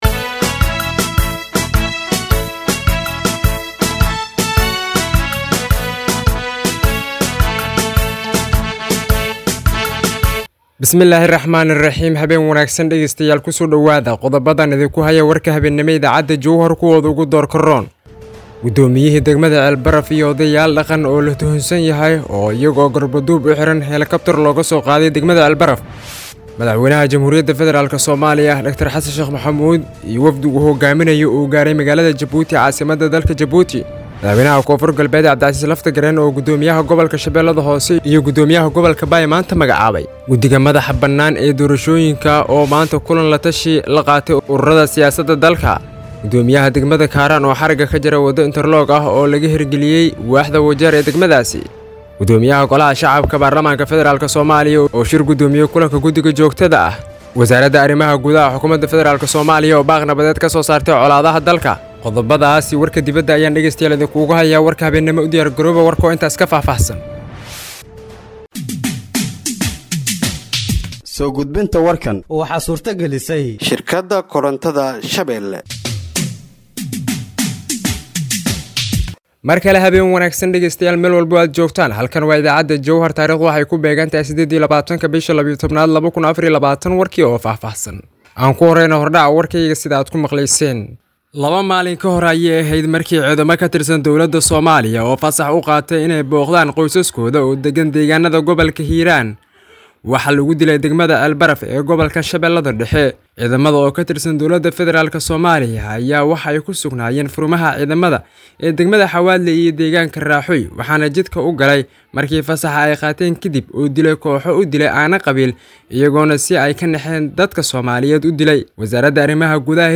Dhageeyso Warka Habeenimo ee Radiojowhar 28/12/2024